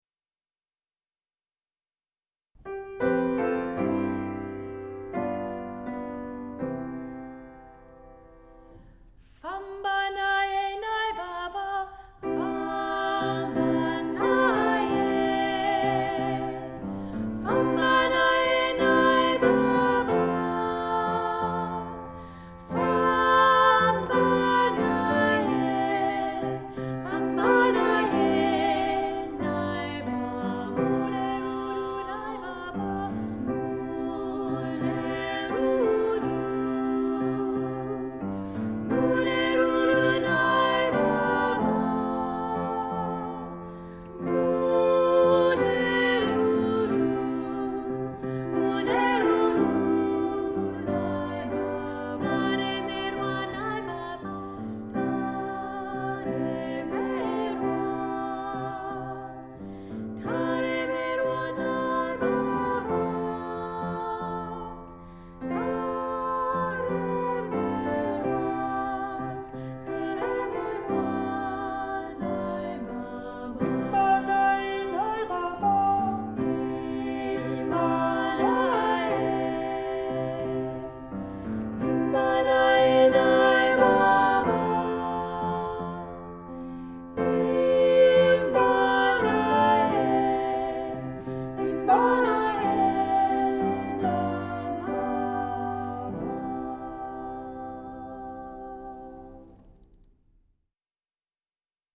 Voicing: Choir